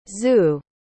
Snoring /z/ | comic Anh Việt